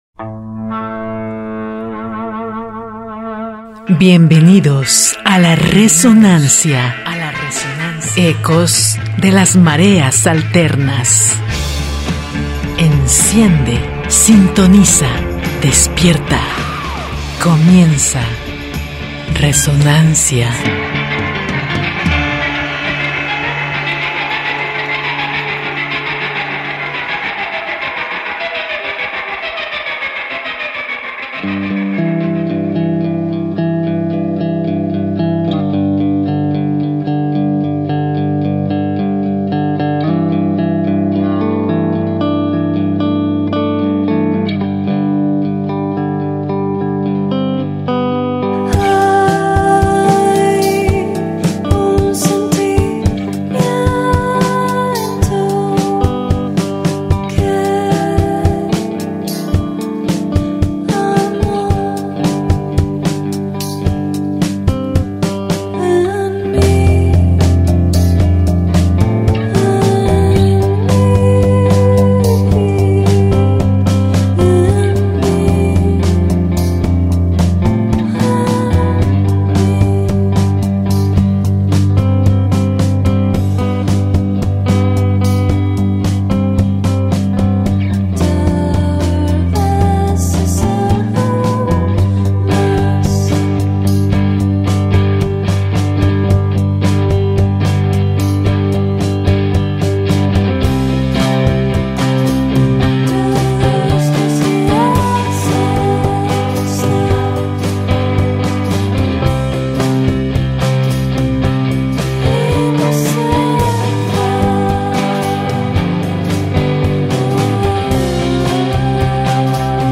psicodelia, garage, noise, stoner, shoegaze, dream y todos los sonidos viajadores